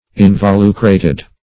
Search Result for " involucrated" : The Collaborative International Dictionary of English v.0.48: Involucrate \In`vo*lu"crate\, Involucrated \In`vo*lu"cra*ted\, a. (Bot.)